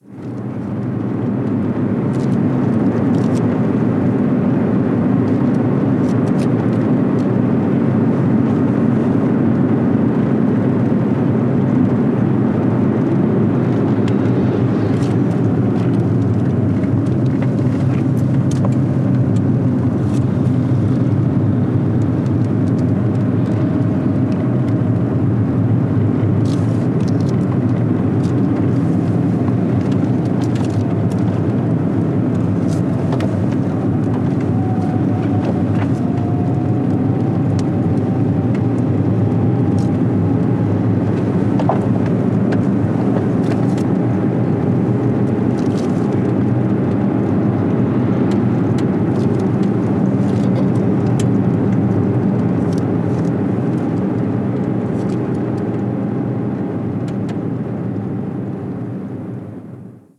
Motor de un coche Golf desde el interior con la ventana abierta
Sonidos: Transportes